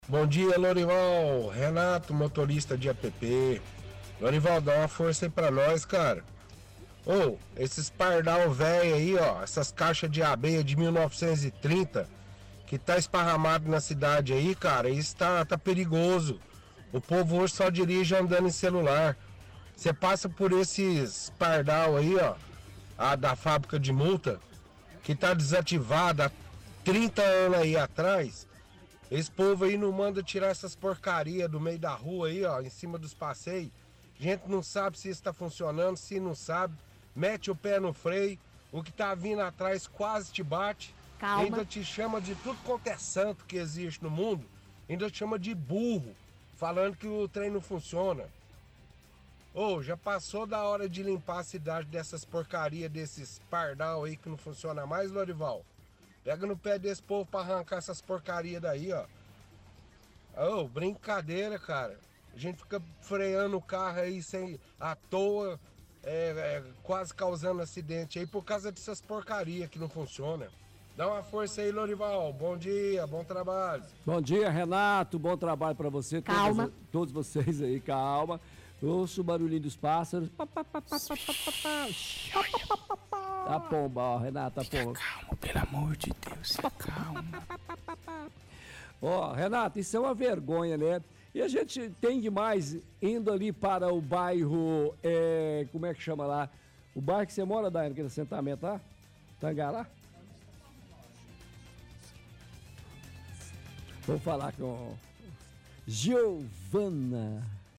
– Ouvinte reclama de radares pela cidade que não funcionam, pois, as pessoas freiam o carro encima da hora e quase causam acidentes.